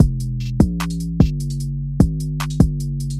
标签： 150 bpm Drum And Bass Loops Drum Loops 554.22 KB wav Key : Unknown FL Studio Mobile
声道立体声